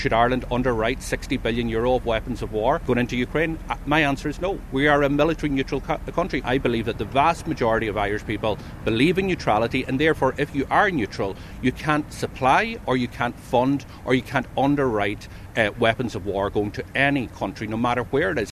Donegal Deputy Pearse Doherty rejects the Taoiseach’s criticism: